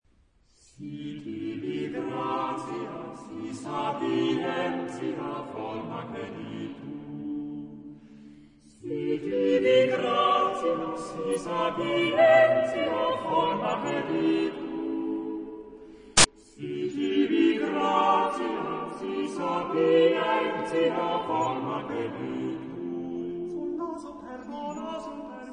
Madrigal Charakter des Stückes: lebhaft
SATB (4 gemischter Chor Stimmen )
Tonart(en): D dorisch